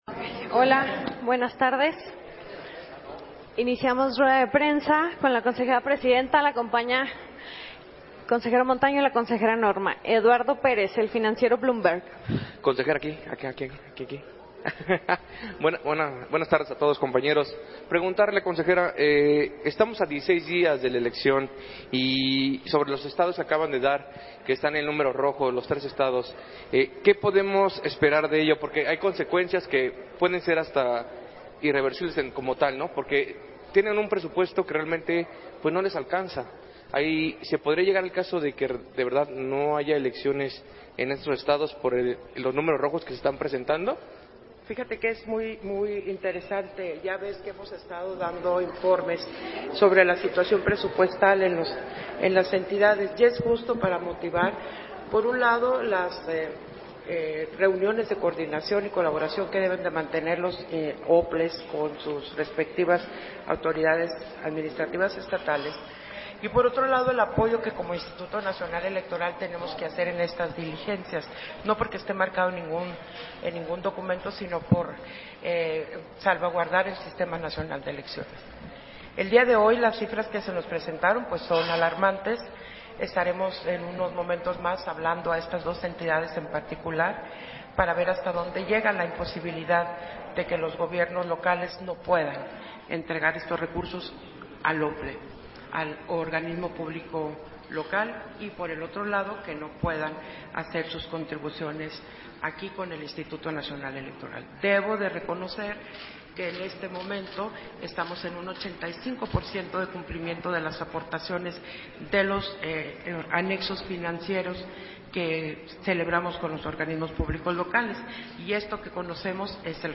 150525-AUDIO-CONFERENCIA-DE-PRENSA-CONSEJERA-PDTA.-DEL-INE-GUADALUPE-TADDEI-ZAVALA
Versión estenográfica de la conferencia de prensa que ofreció la Consejera Presidenta del INE, Guadalupe Taddei, al termino de la Sesión Extraordinaria del Consejo General del 15 de mayo de 2025